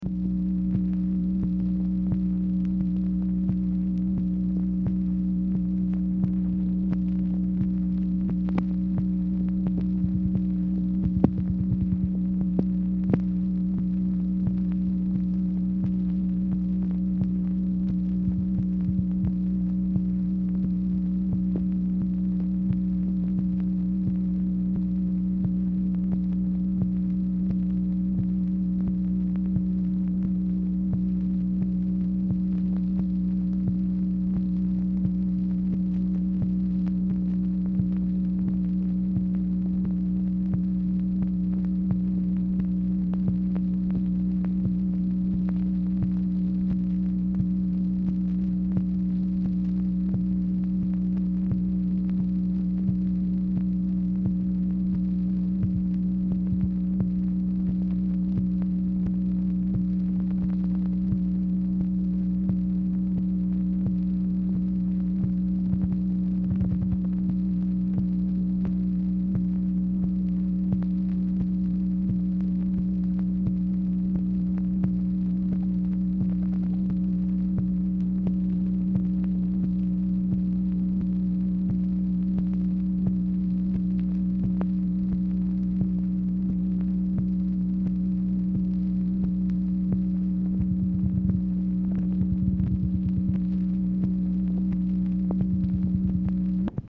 Telephone conversation # 10120, sound recording, MACHINE NOISE, 5/13/1966, time unknown | Discover LBJ